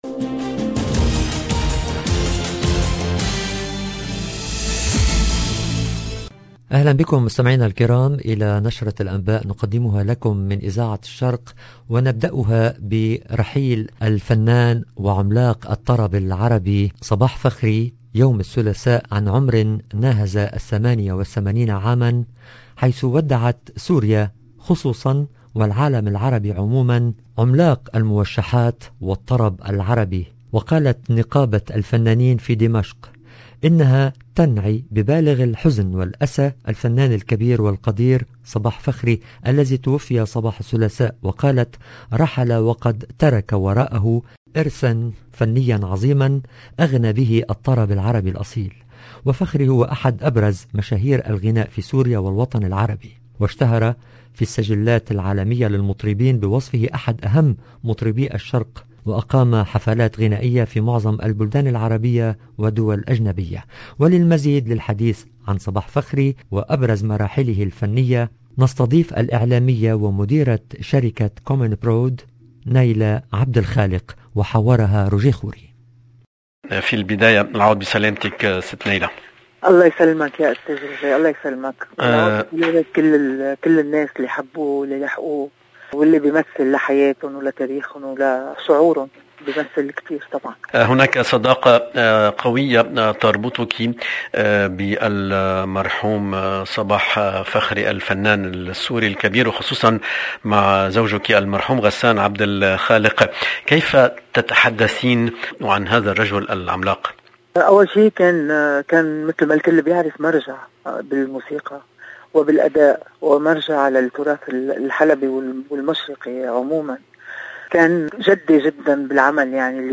EDITION DU JOURNAL DU SOIR EN LANGUE ARABE